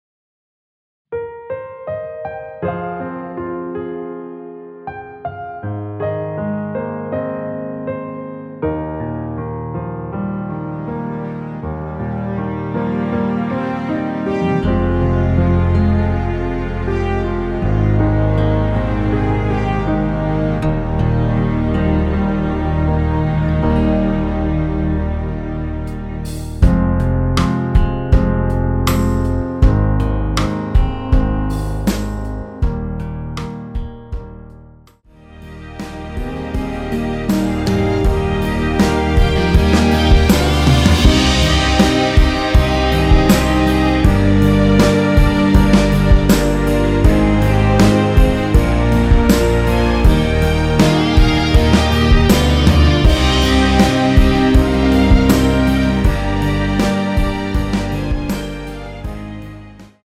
원키에서(-3)내린 MR입니다.
Eb
앞부분30초, 뒷부분30초씩 편집해서 올려 드리고 있습니다.